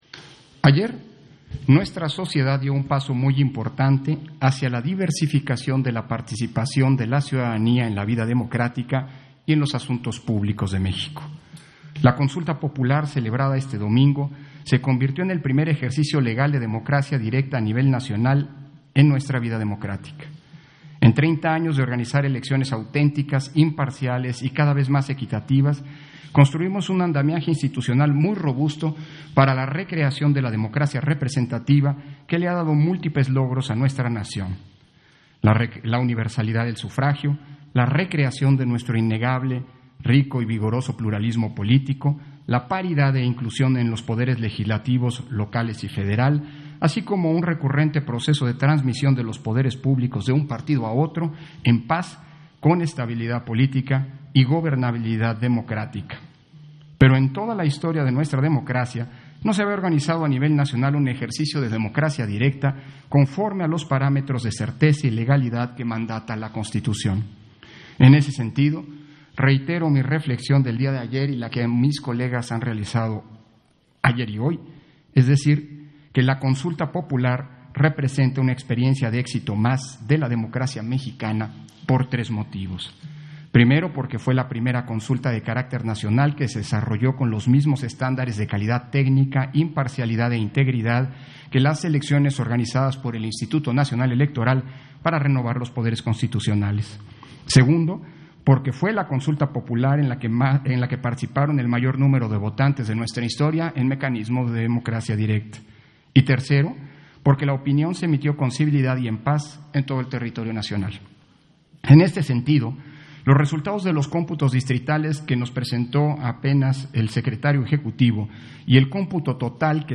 020821_AUDIO_-INTERVENCIÓN-CONSEJERO-PDTE.-CÓRDOVA-PUNTO-6-SESIÓN-EXT.